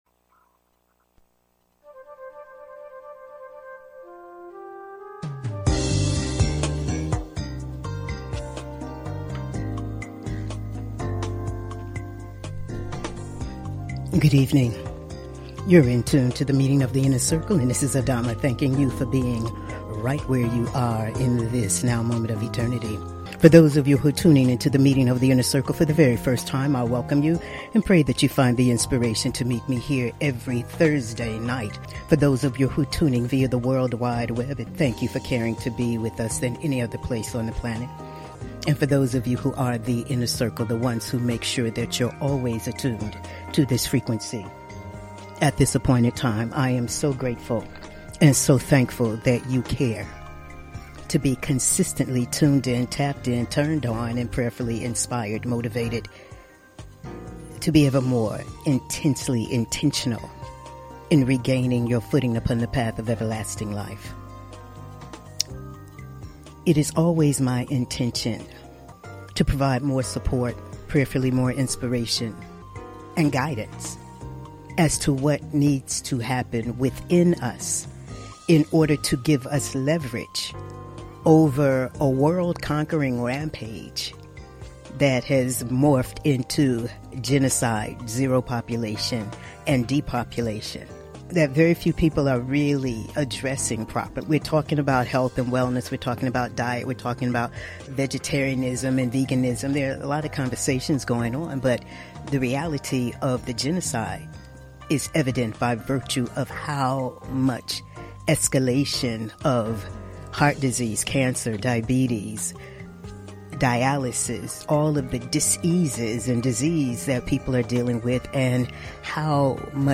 Talk Show Episode
Monologues